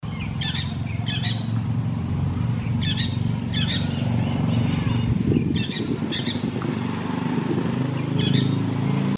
Lorita Pico Negro (Aratinga weddellii)
Nombre en inglés: Dusky-headed Parakeet
Localidad o área protegida: Buena Vista
Condición: Silvestre
Certeza: Observada, Vocalización Grabada